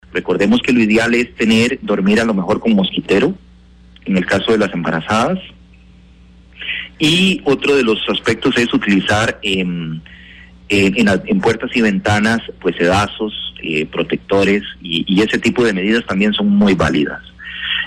Así lo manifestó el titular de la cartera, Fernando Llorca, durante el programa Nuestra Voz la mañana de este miércoles.
ministro de salud, fernando llorca